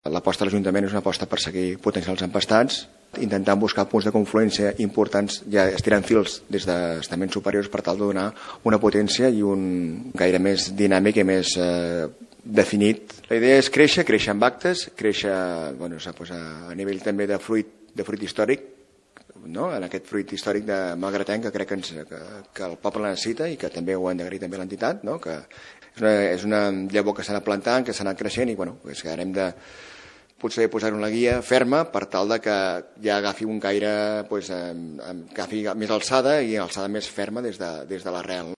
Ho explica el regidor de cultura de Malgrat, Albert Cuní